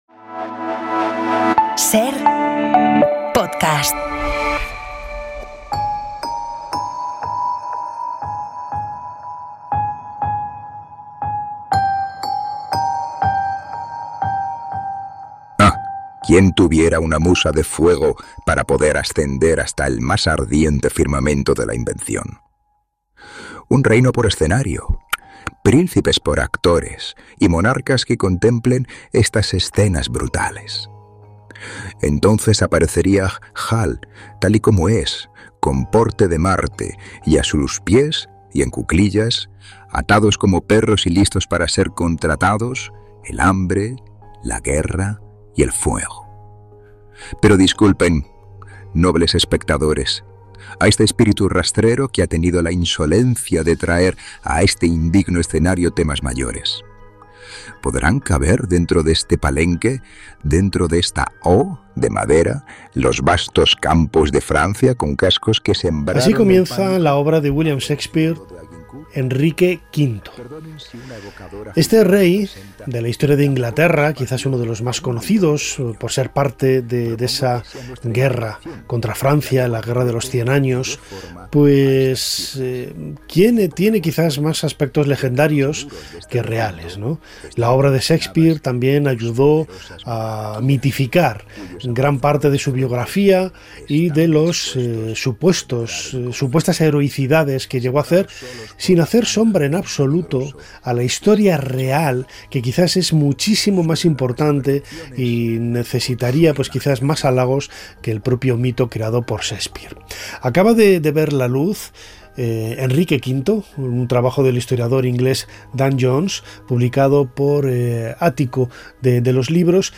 Nos visita Dan Jones, historiador británico y autor de una biografía de Enrique V, joven rey inglés que derrotó a la flor y nata de la nobleza francesa y se convirtió en una figura legendaria